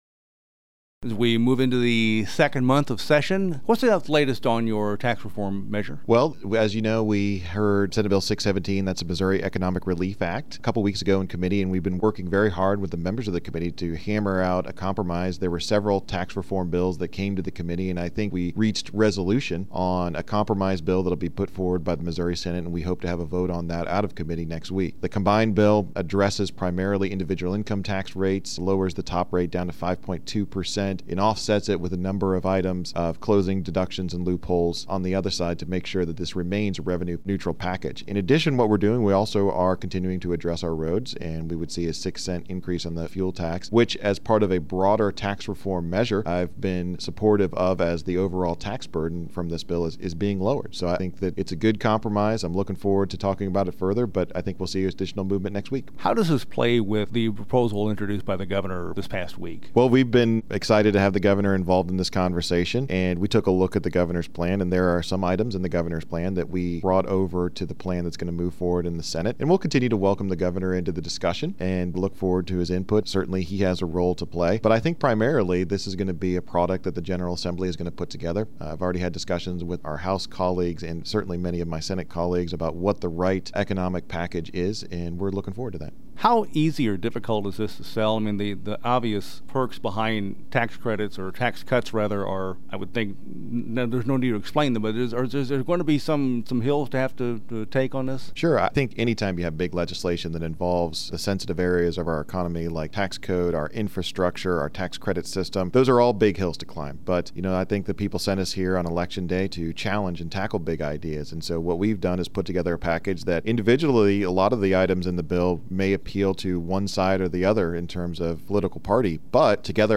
JEFFERSON CITY — State Sen. Bill Eigel, R-Weldon Spring, discusses the status of Senate Bill 617, legislation that seeks to modify several provisions relating to taxation.